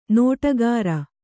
♪ nōṭagāra